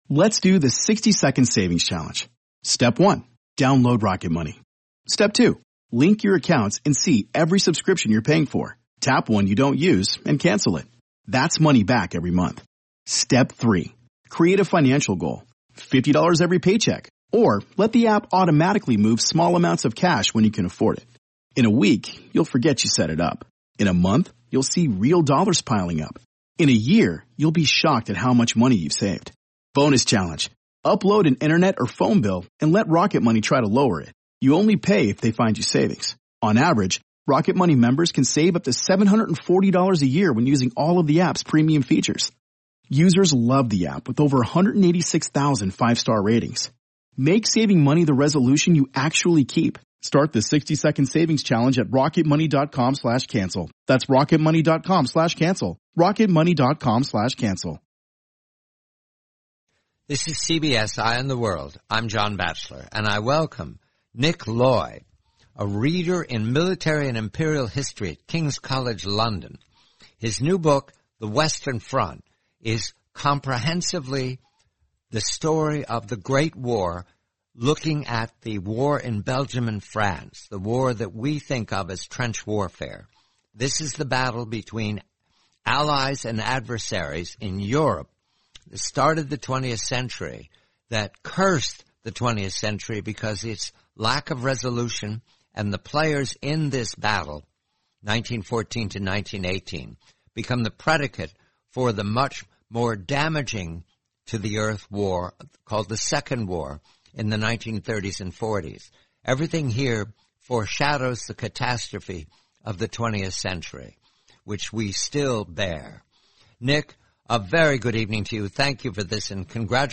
The complete eighty-minute interview.